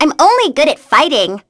Sonia-vox-get-02.wav